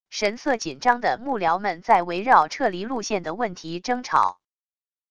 神色紧张的幕僚们在围绕撤离路线的问题争吵wav音频